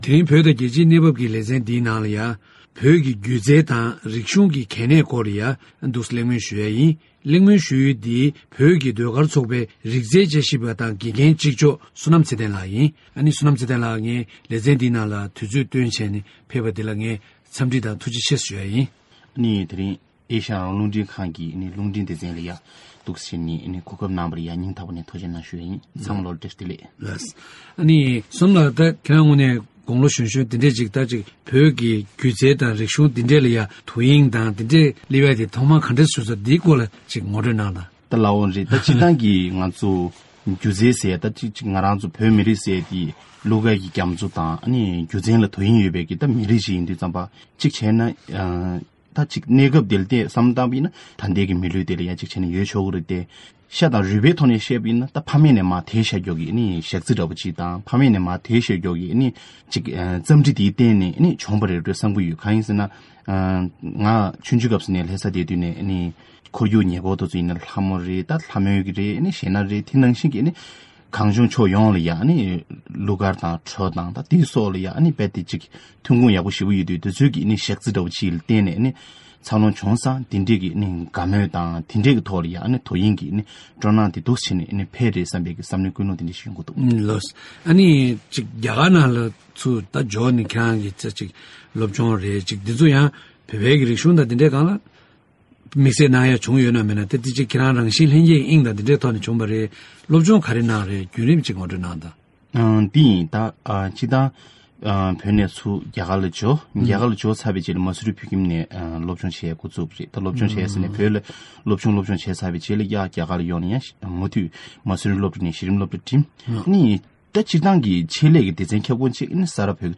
ལྷན་གླེང་བ།